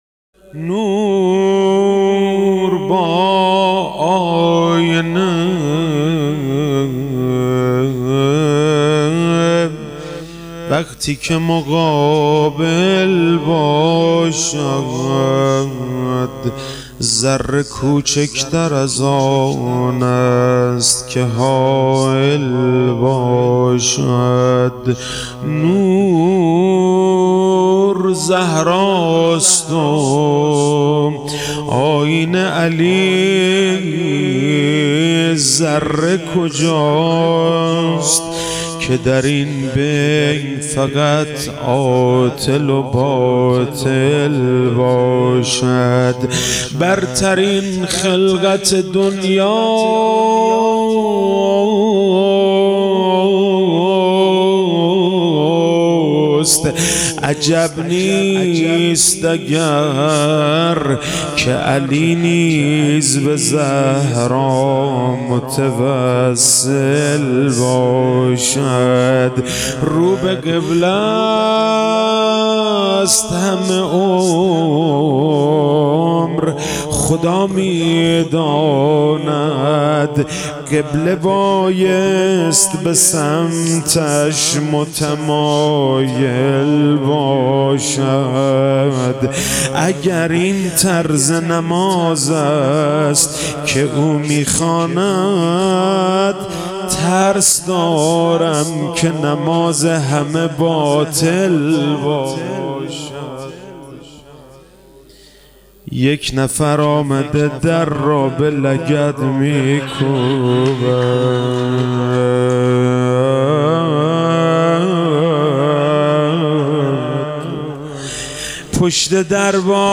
فاطمیه97